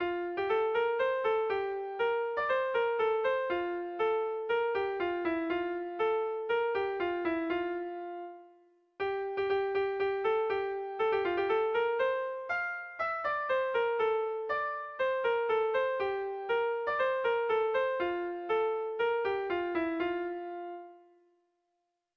Irrizkoa
ABDDEFG